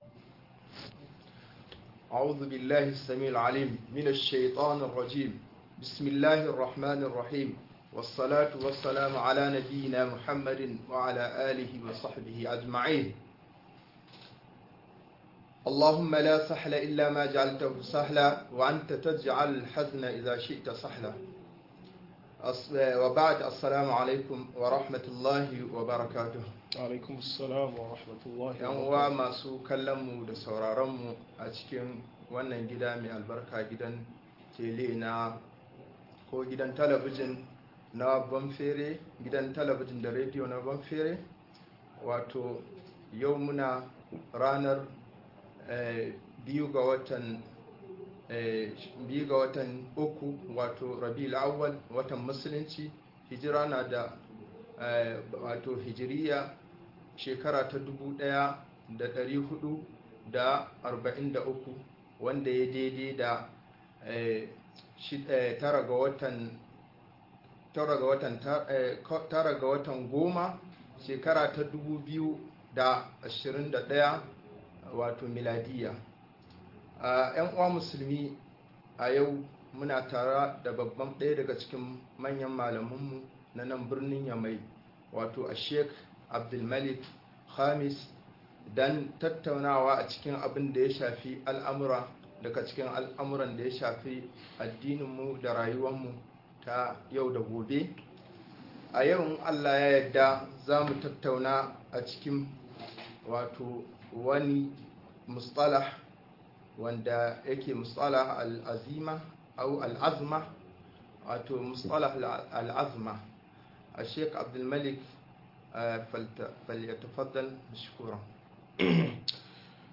Asalin fitintinun duniya - MUHADARA